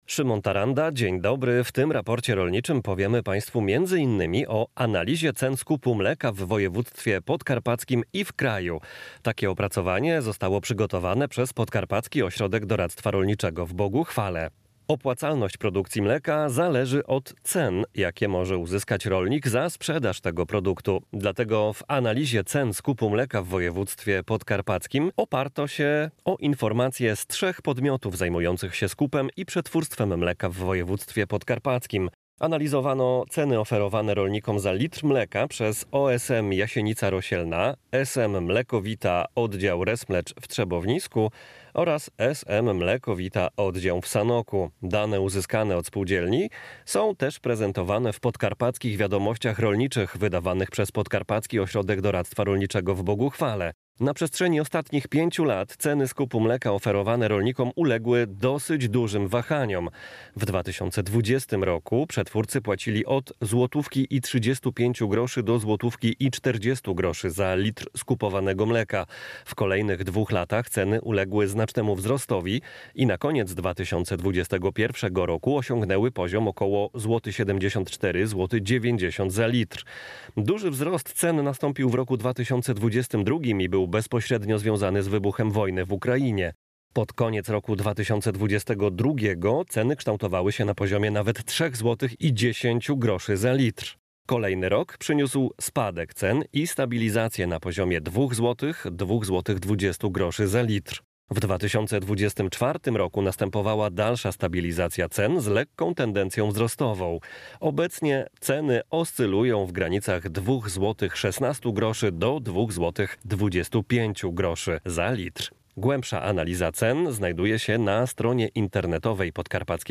W tym "Raporcie rolniczym" rozmawiamy o analizie cen mleka, pasz i nawozów, przeprowadzonej przez Podkarpacki Ośrodek Doradztwa Rolniczego